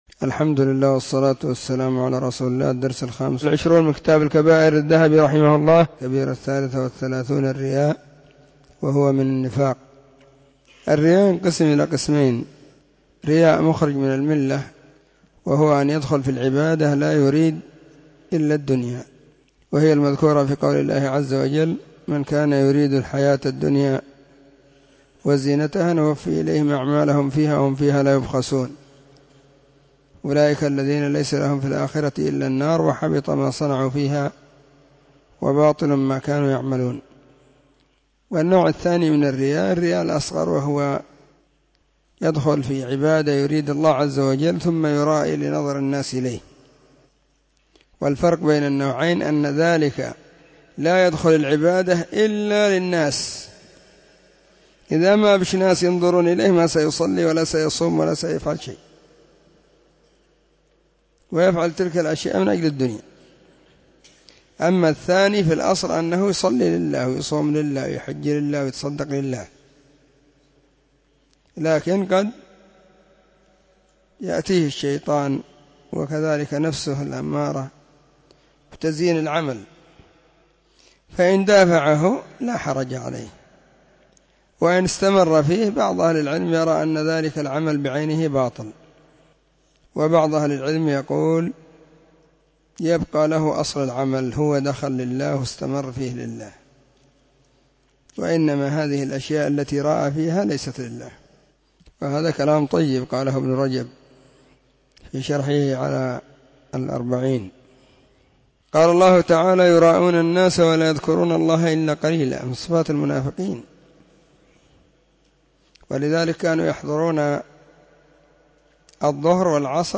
🕐 [بين مغرب وعشاء – الدرس الثاني]
📢 مسجد الصحابة – بالغيضة – المهرة، اليمن حرسها الله.